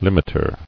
[lim·it·er]